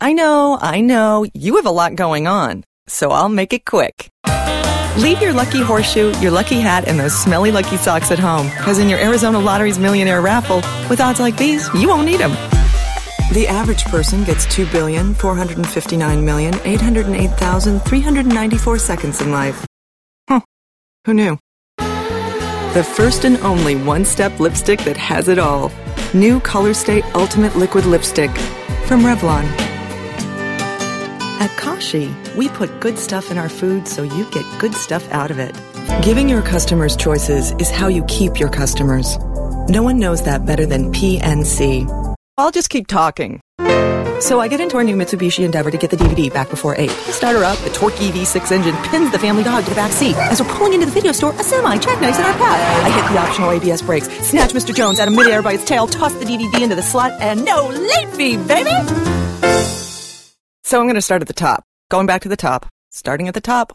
Native English speaker from Los Angeles, California, specializing in commerical, promo, and narration.
Sprechprobe: Werbung (Muttersprache):
Conversational, warm, friendly, savvy, smart, quirky, funny, humorous, believable, authoritative, sophisticated, engaging